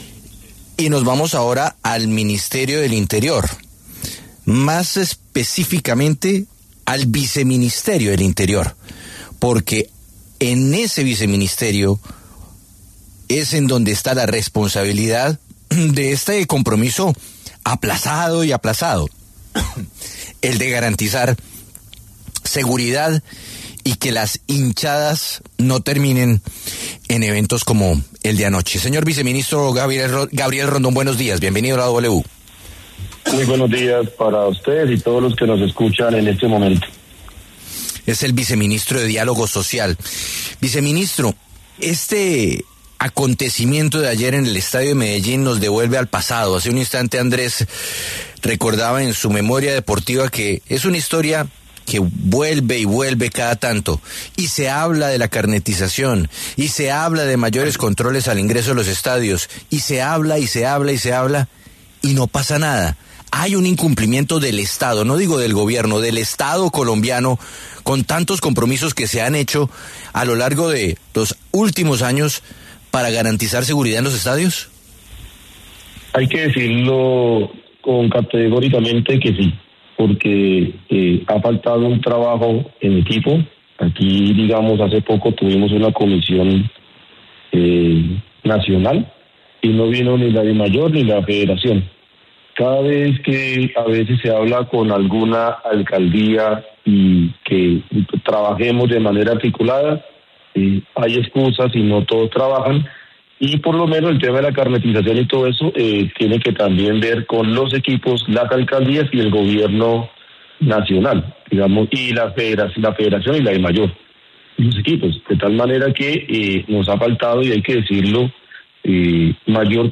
En entrevista con La W, Rondón fue enfático en señalar que ha faltado un trabajo conjunto real entre todos los actores responsables.